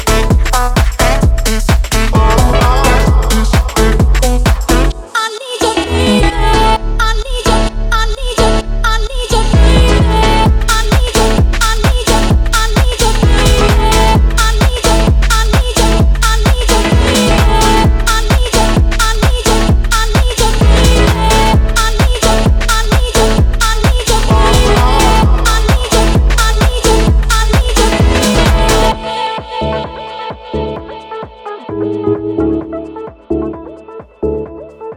Dance Electronic
Жанр: Танцевальные / Электроника